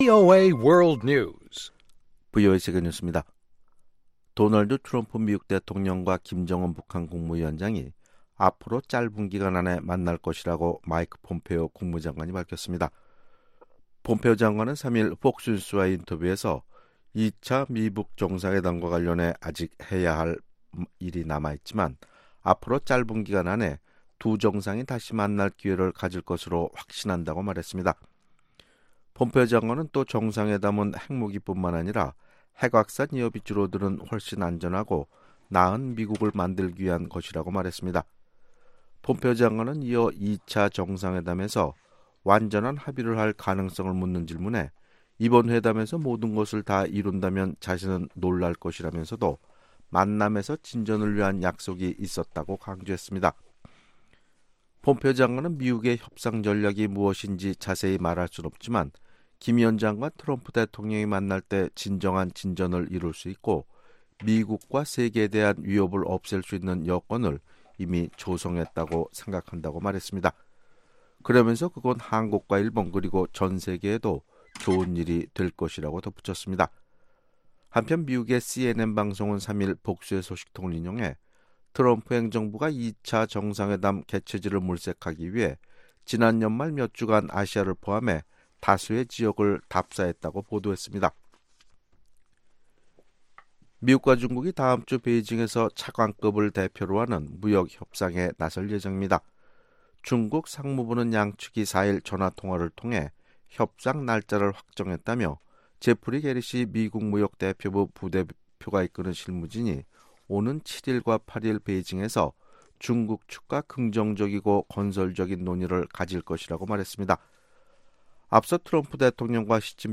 VOA 한국어 아침 뉴스 프로그램 '워싱턴 뉴스 광장' 2019년 1월 5일 방송입니다. 3일 개원한 미국 의회에서 의원들은 북한 문제에 대해 외교를 통한 해결을 지지한다면서도 북한이 구체적인 비핵화 조치를 취할 때까지 제재는 계속돼야 한다는 초당적인 입장을 보였습니다. 미국의 전직 관리들은 이탈리아 주재 북한 외교관의 잠적 소식과 관련, 정권의 안정성 여부와 성급히 결론 짓기 어렵다는 신중한 반응을 보였습니다.